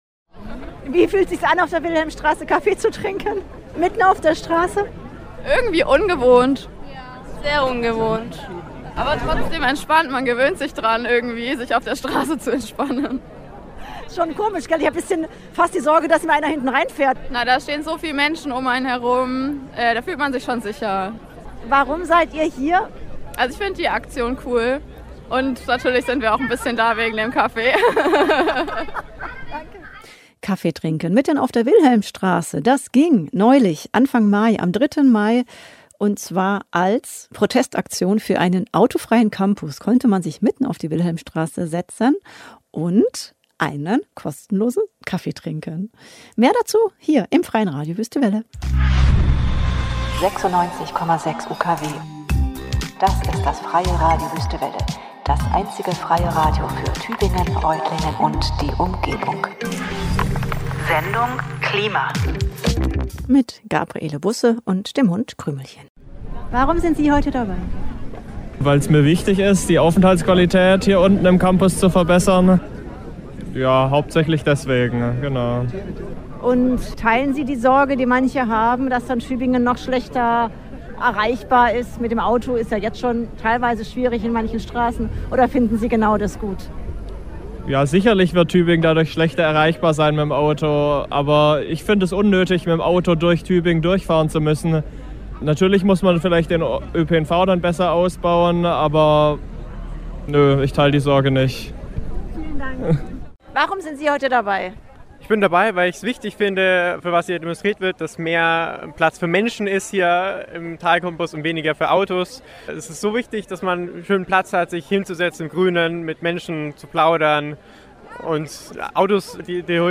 Außerdem zu hören: die Stimmen von begeisterten Menschen, die an der Aktion teilnahmen und sich wünschten, dass ein ruhiger Campus nicht nur für fünf Minuten möglich sein sollte, sondern für immer - und dass die Wilhelmstraße für Menschen da ist statt vor allem für Autos.